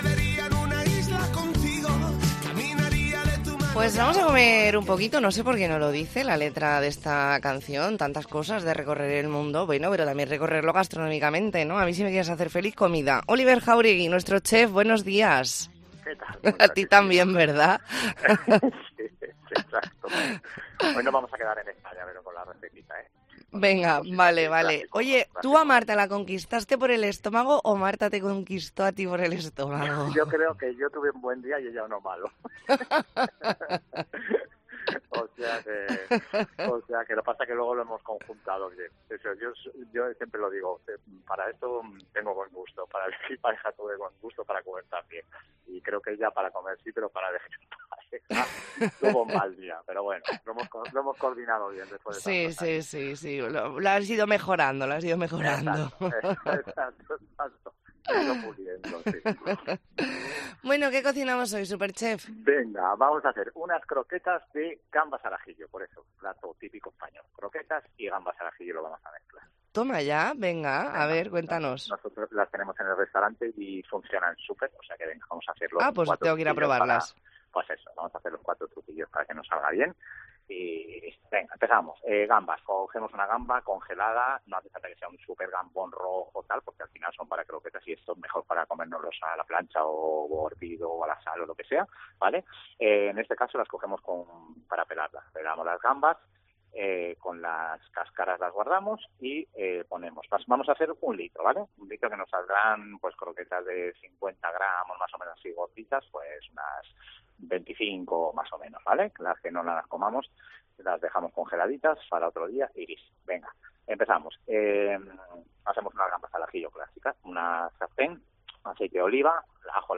Entrevista en La Mañana en COPE Más Mallorca, lunes 27 de noviembre de 2023.